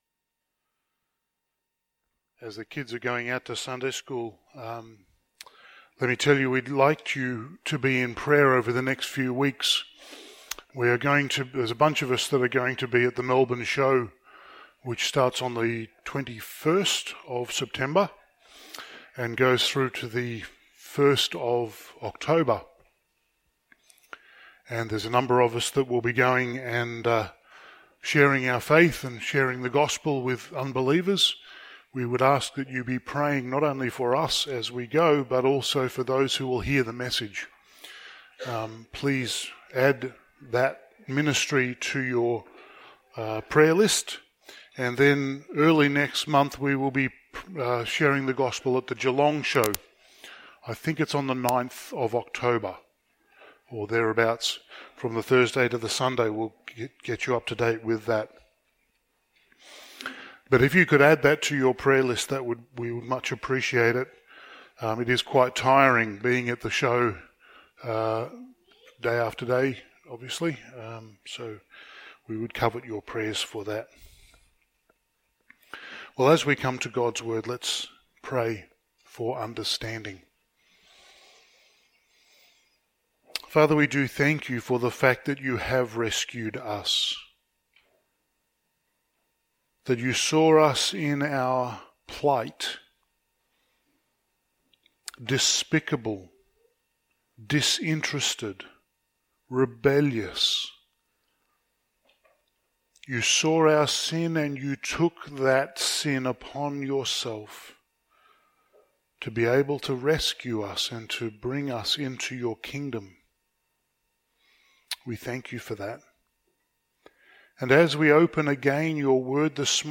Passage: 2 Samuel 18:1-19:8 Service Type: Sunday Morning